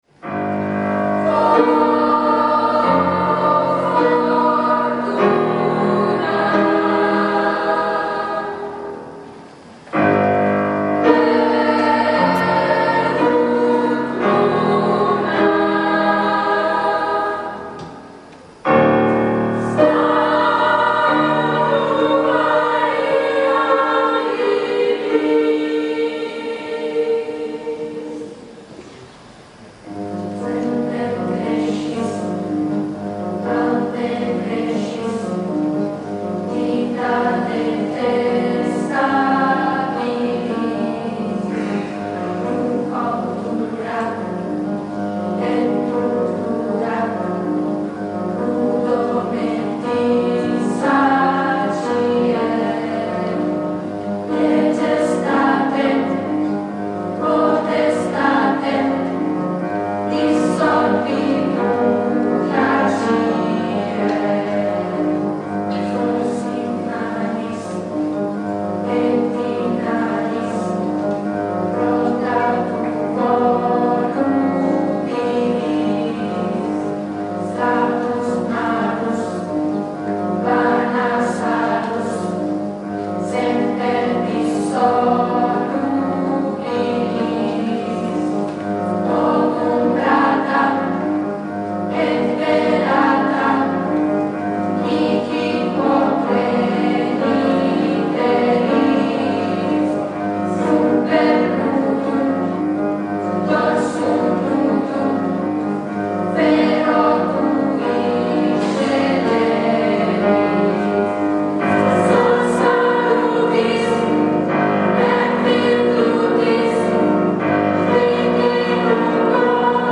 adattamento per coro
GenereCori